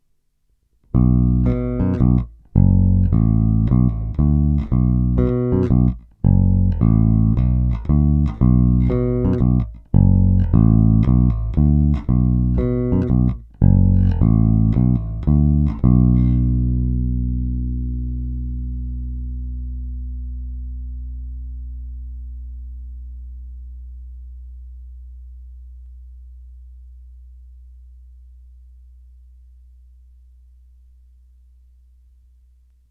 Následující nahrávky jsou provedeny rovnou do zvukové karty a dále ponechány bez jakýchkoli úprav, kromě normalizace samozřejmě. Použité struny jsou neznámé niklové pětačtyřicítky ve výborném stavu.
Oba snímače